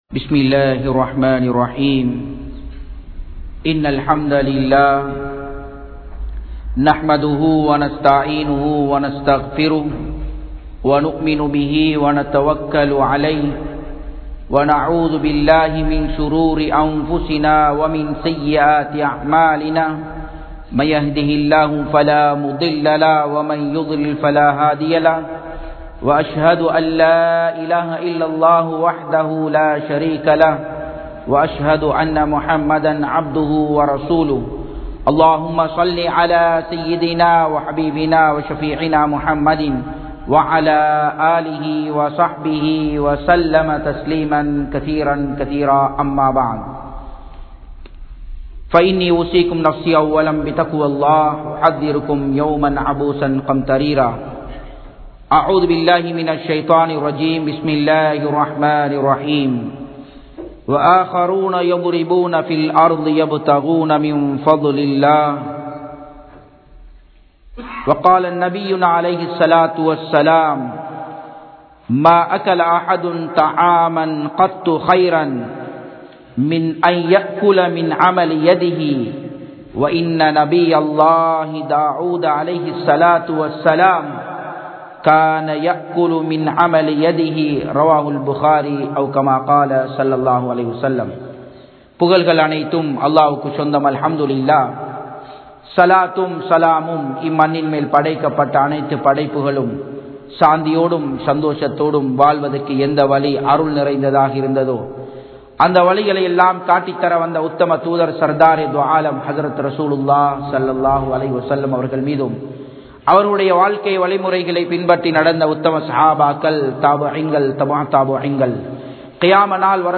Sirantha Viyafari Yaar? (சிறந்த வியாபாரி யார்?) | Audio Bayans | All Ceylon Muslim Youth Community | Addalaichenai
Japan, Nagoya Port Jumua Masjidh 2017-11-10 Tamil Download